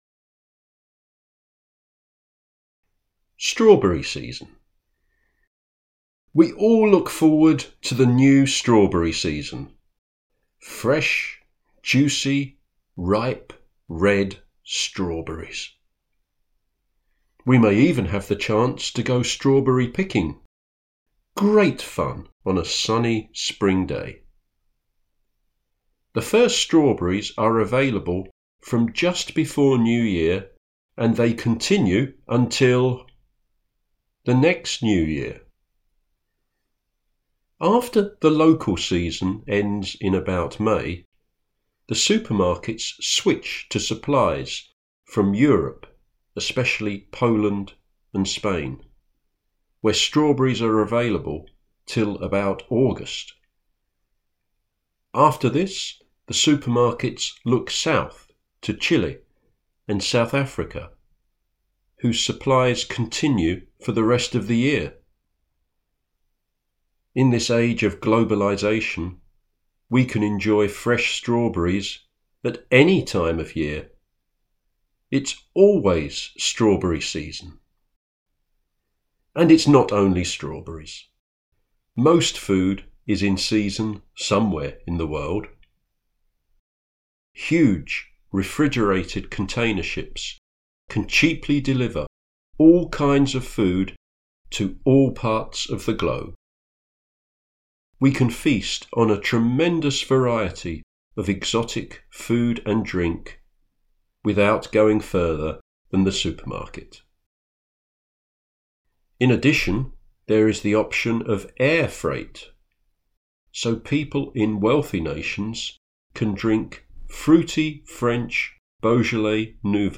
Strawberry Season audio narration